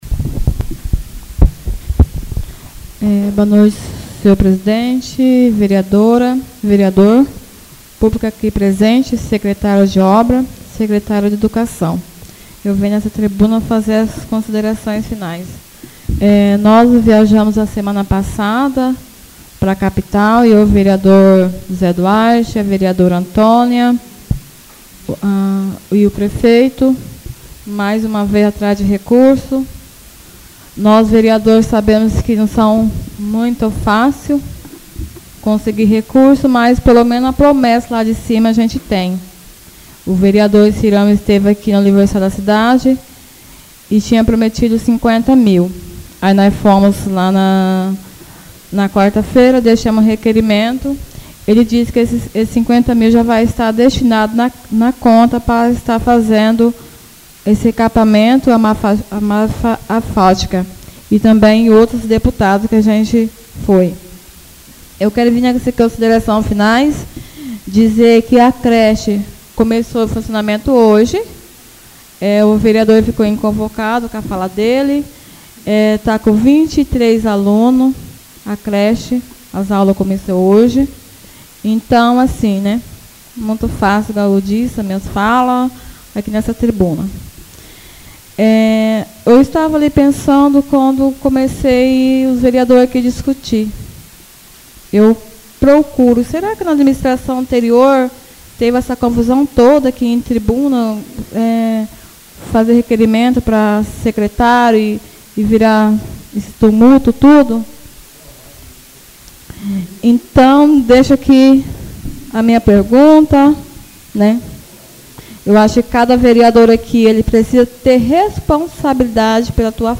Oradores das Explicações Pessoais (25ª Ordinária da 3ª Sessão Legislativa da 6ª Legislatura)